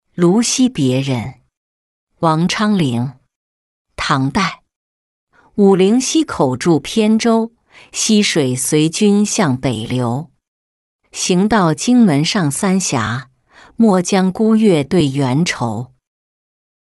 卢溪别人-音频朗读